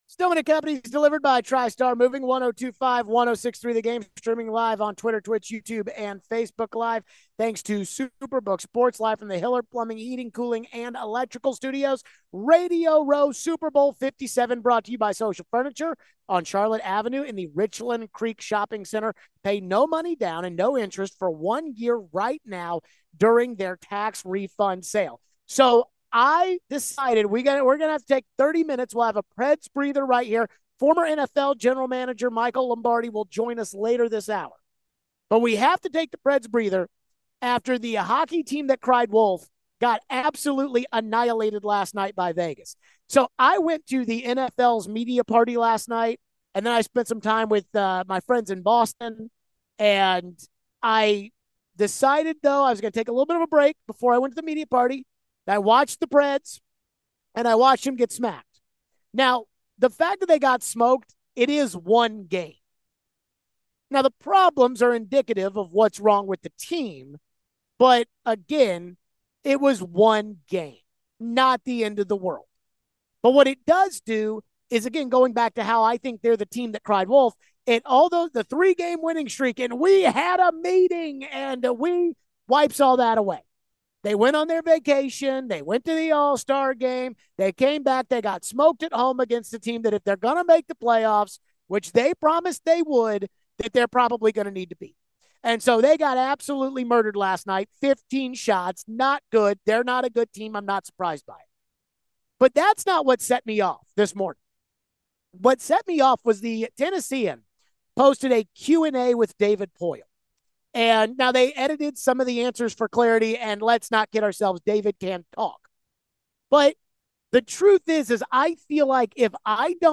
Do we think Mike Vrabel is hard to work for as a coach? Former NFL executive Mike Lombardi joins the show from Radio Row.